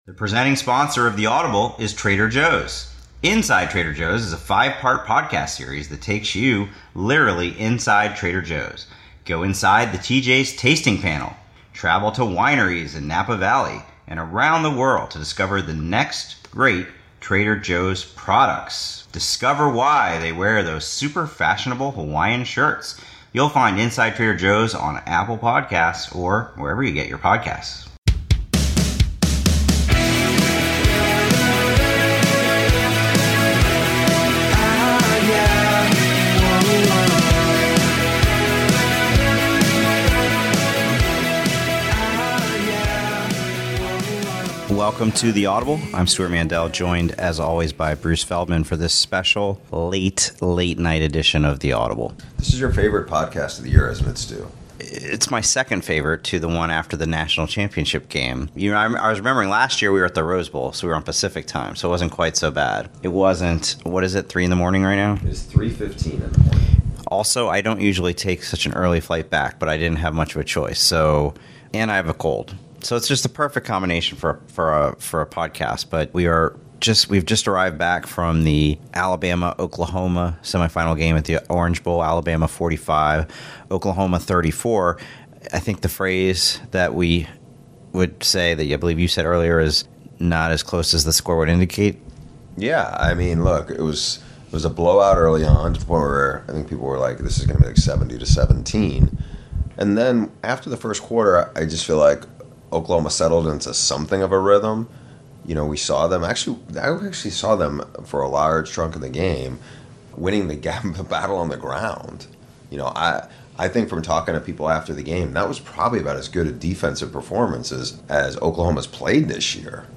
a special late night edition of The Audible live from Miami. They start the podcast breaking down what happened in the Orange and Cotton Bowls (1:00); they look ahead to which bowls between now and the National Championship they are most looking forward to (21:30); and they wrap up the podcast discussing the Houston/Major Applewhite situation (30:45).